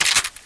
Index of /fastdl/cstrike/sound/weapons
p90_magin.wav